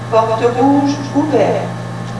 porte_rouge.wav